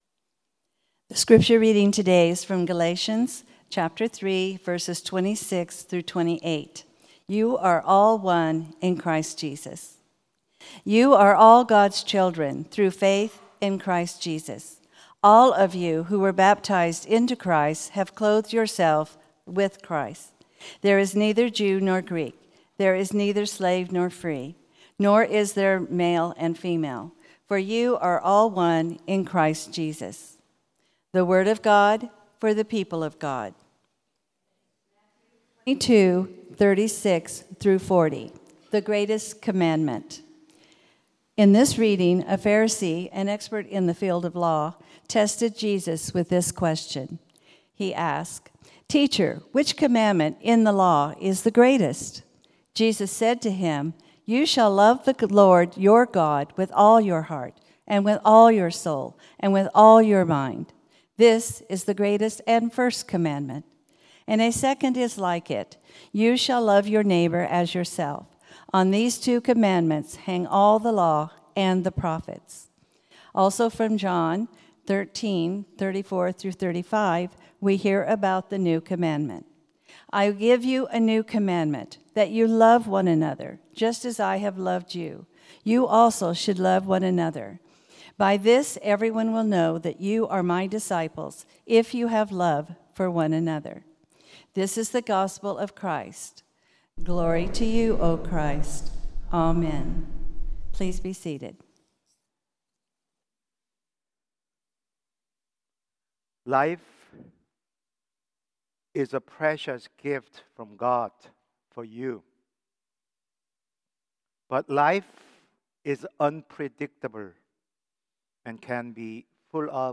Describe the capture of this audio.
Ninth Sunday after Pentecost sermon